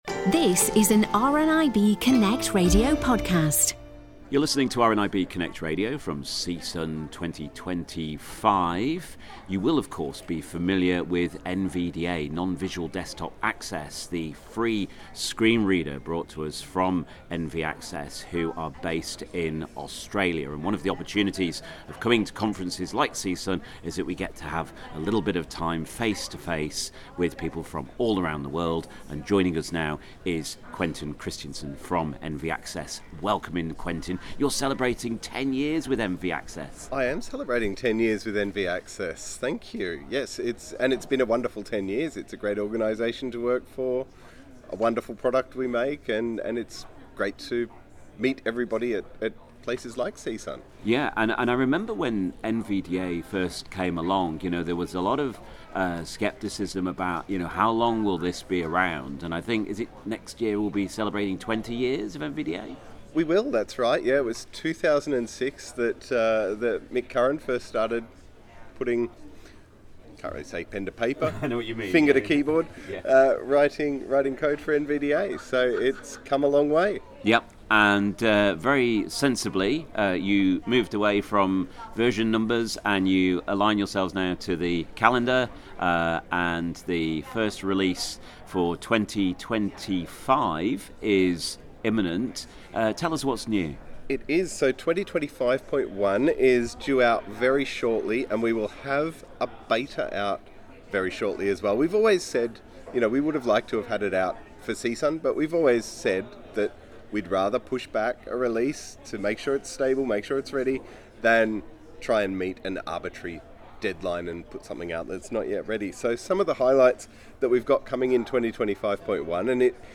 The annual CSUN conference in California brings together some of the biggest names in accessible technology from across the globe.